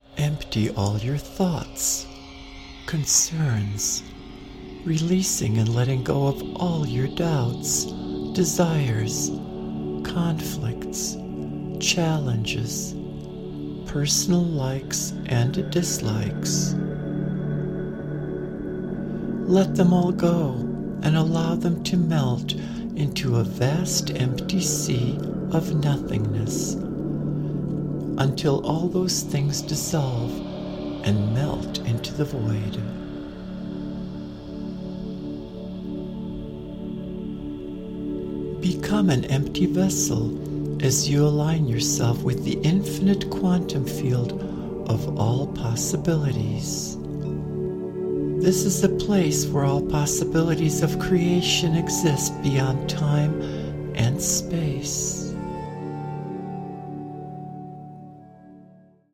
Recorded in 432 Hz Solfeggio for healing and grounding.